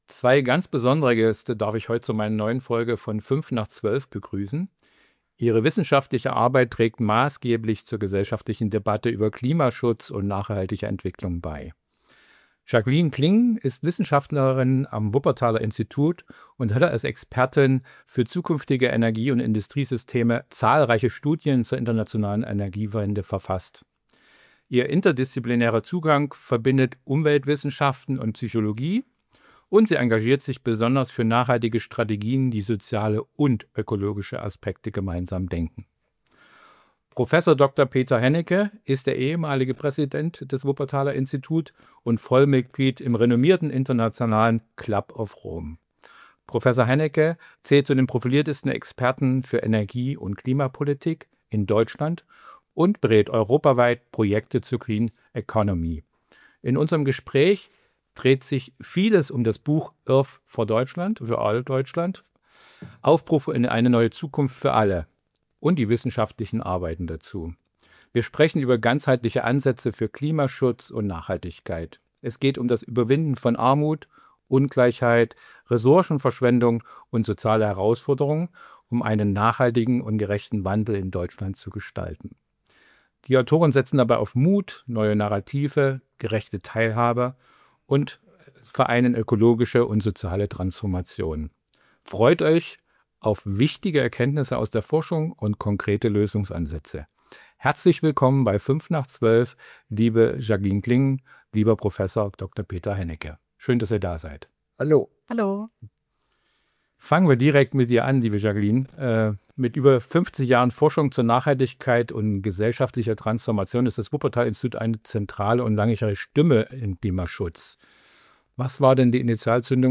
Der Club of Rome und das Wuppertal Institut im Gespräch. Was braucht es, um den großen Wandel wirklich zu schaffen?
Ein Gespräch voller Fakten, Visionen und Zuversicht: Wie schaffen wir eine Zukunft für alle, gerecht, lebenswert und nachhaltig?